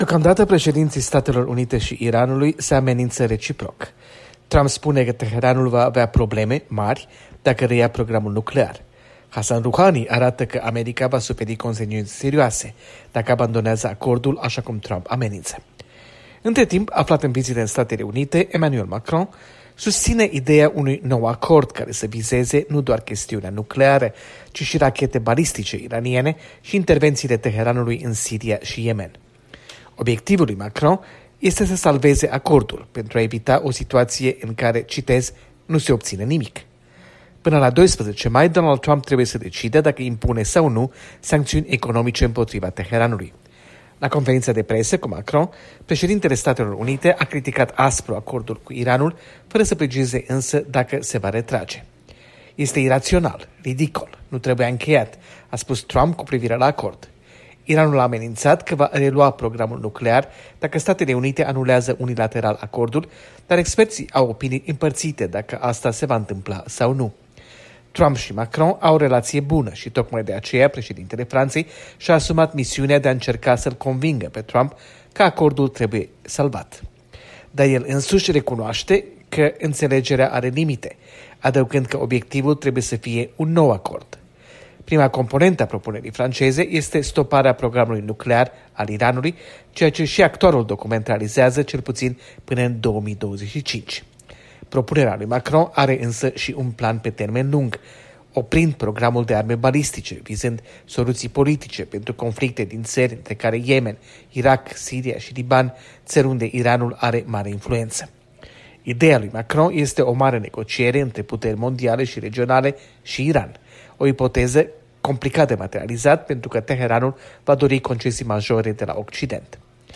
Corespondența zilei de la Washingon